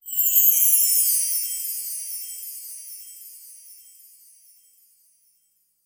METRO-CHIME-DS2.wav